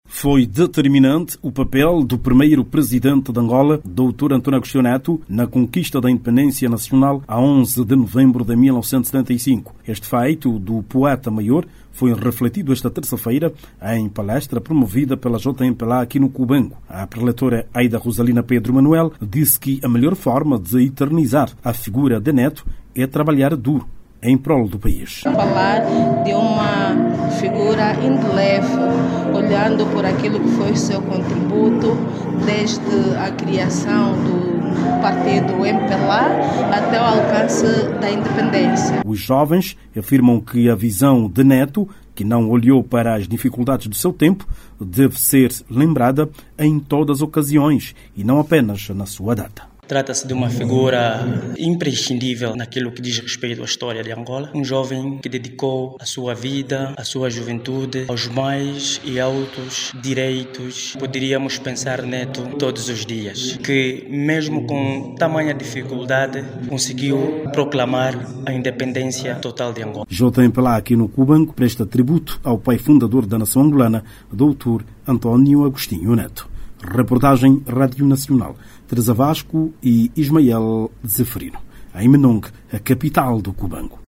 O braço juvenil do partido dos camaradas na Província do Cubango, abordou o papel de Agostinho Neto na conquista da Independência Nacional. A JMPLA no Cubango, acredita que a melhor forma de eternizar a figura de Neto é trabalhar duro em prol da Nação. Saiba mais dados no áudio abaixo com o repórter